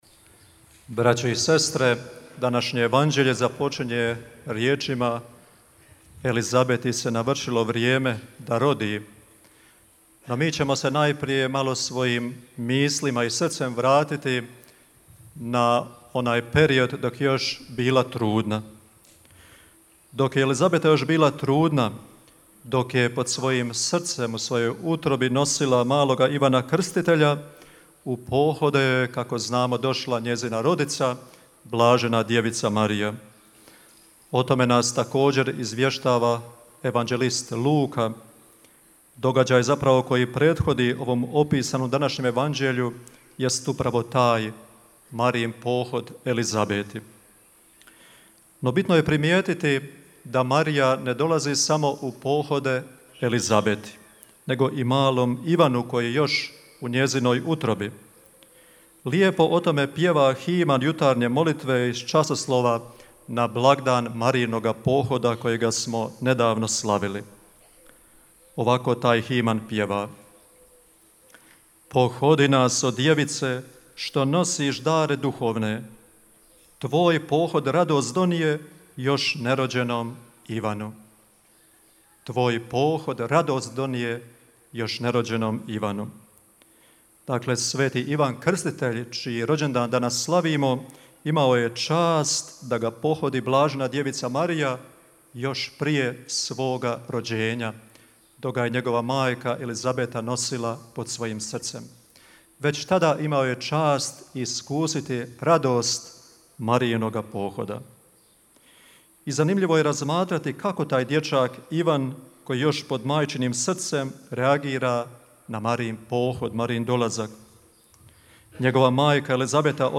Homilija